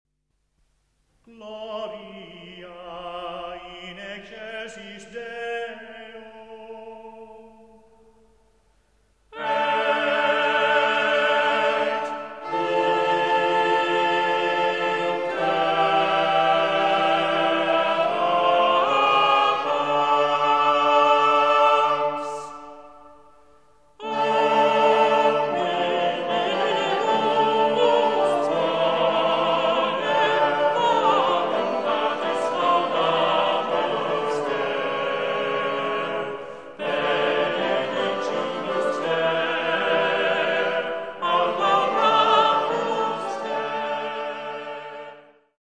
(1300-1377) Het is een vierstemmige zetting van het ordinarium.
• de vierstemmige zetting (in die tijd ongebruikelijk)